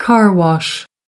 2. car wash (n) /kɑr wɑʃ/ việc rửa xe ô-tô (đề gây quỹ từ thiện)